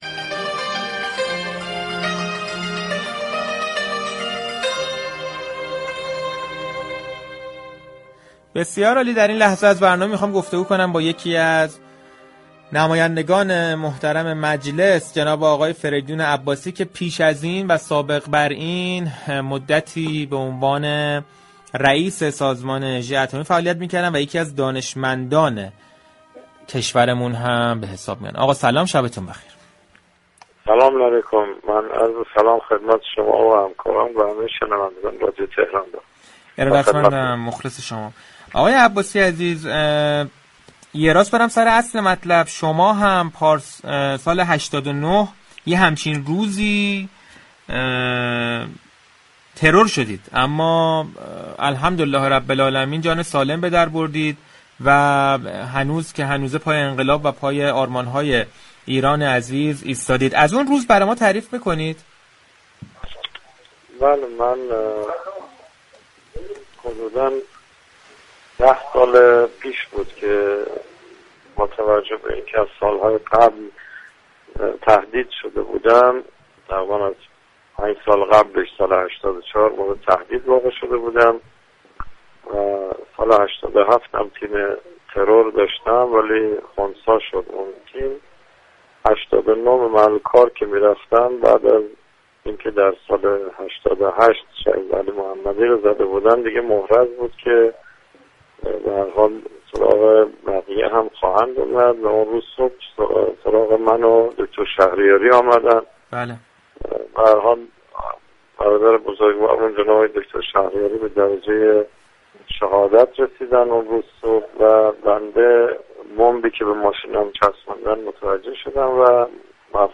به گزارش پایگاه اطلاع رسانی رادیو تهران، فریدون عباسی رئیس اسبق سازمان انرژی اتمی در گفتگو با برنامه صحنه رادیو تهران با اشاره به حادثه ترور سال 89 كه به مصدومیت وی و همسرش و شهادت مجید شهریاری دانشمند هسته ای كشورمان منجر شد گفت: من از سال 84 تهدید شدم و سال 87 هم عملیات ترور من خنثی شد.